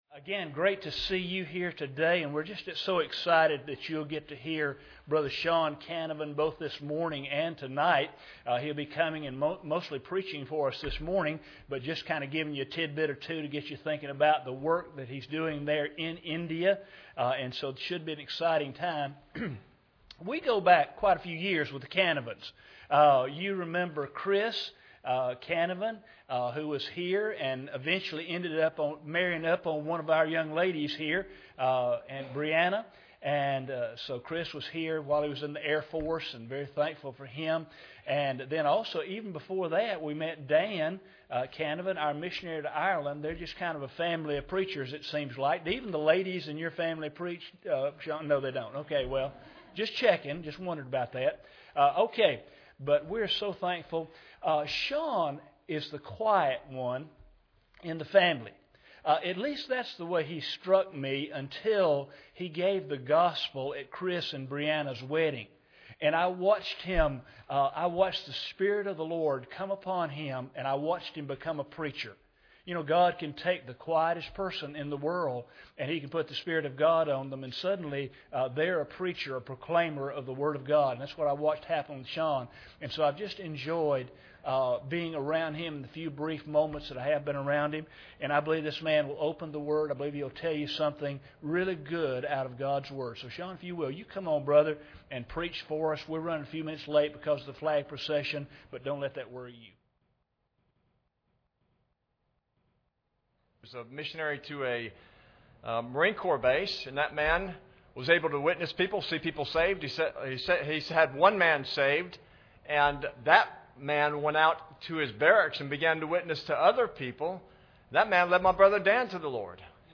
Series: 2014 Missions Conference
Service Type: Sunday Morning